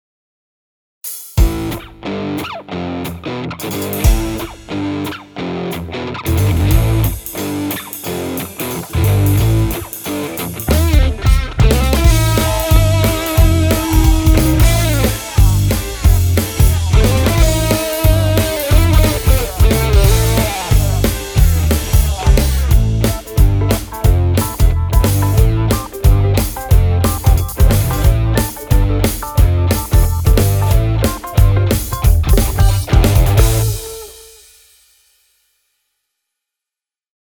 Всё, кроме барабасов ;) сделано через ПОД.